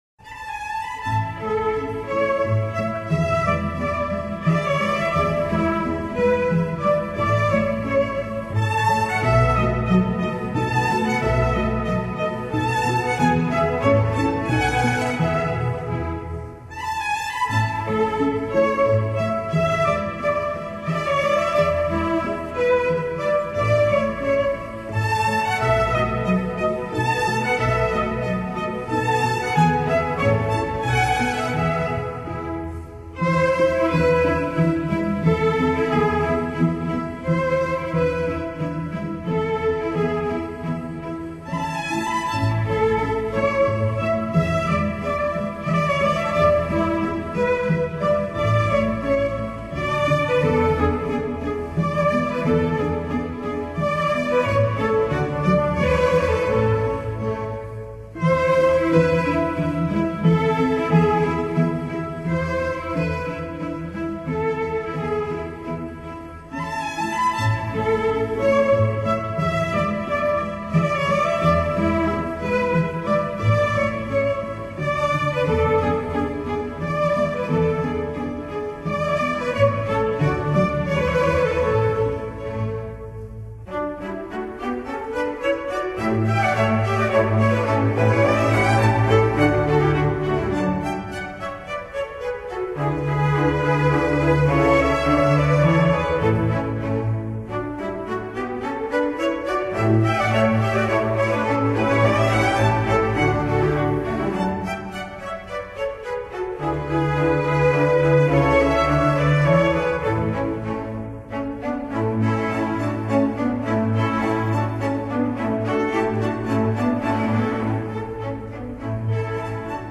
Genre: Instrumental, Classic
精选12首舒缓美妙的轻松古典音乐